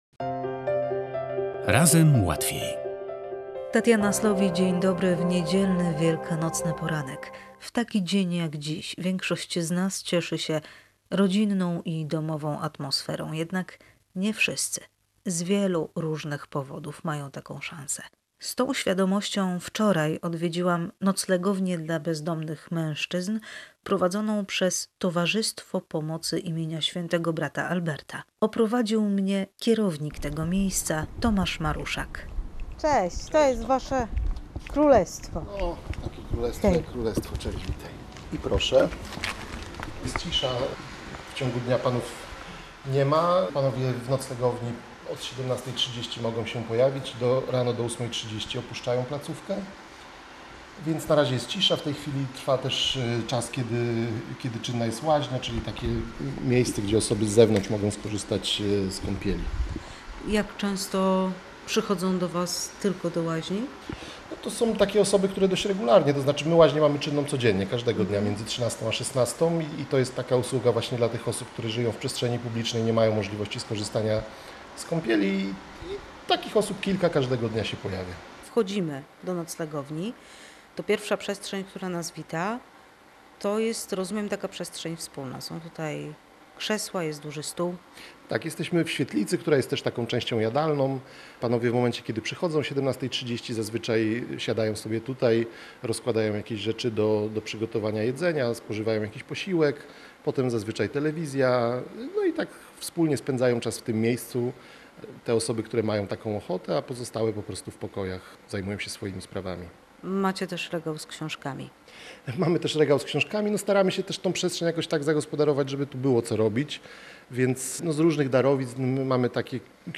Dźwiękowa wędrówka po korytarzach noclegowni dla osób w kryzysie bezdomności
W audycji „Razem Łatwiej” dźwiękowa wędrówka po korytarzach noclegowni oraz rozmowa o tym, że kryzys bezdomności to nie tylko kryzys materialny.